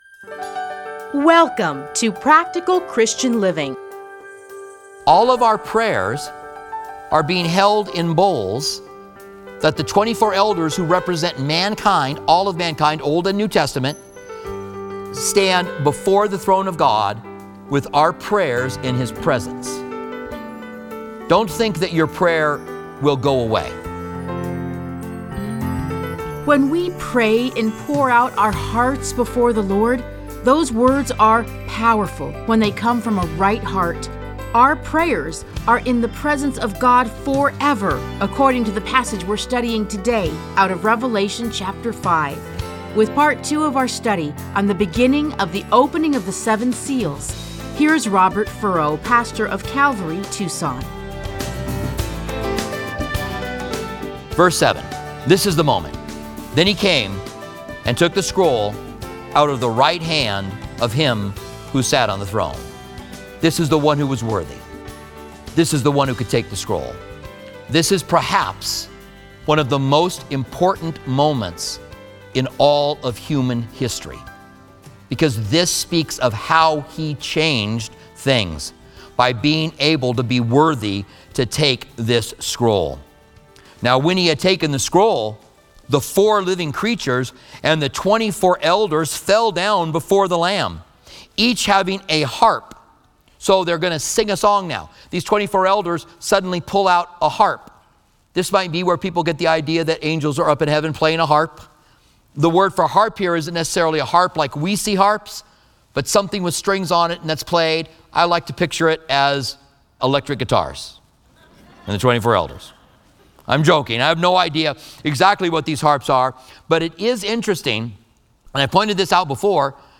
Listen to a teaching from Revelation 5:1-14.